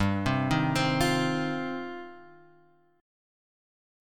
G 7th Suspended 4th Sharp 5th
G7sus4#5 chord {3 3 1 x 1 1} chord